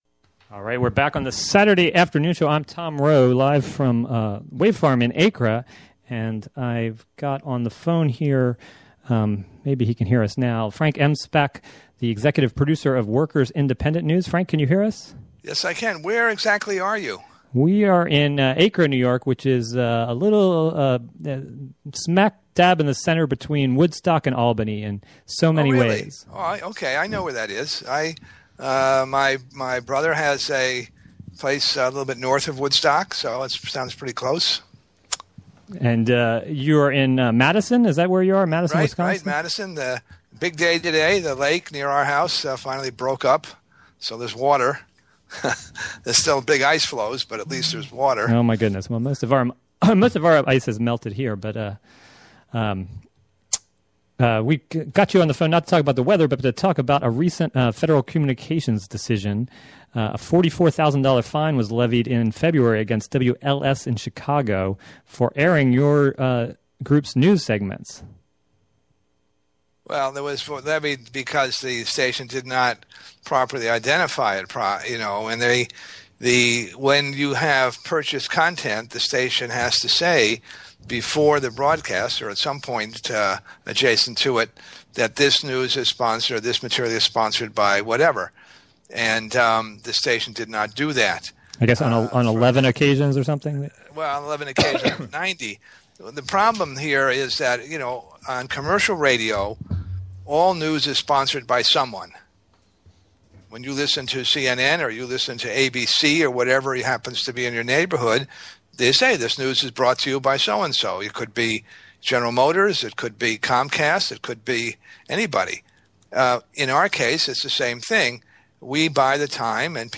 WGXC 90.7-FM: Radio for Open Ears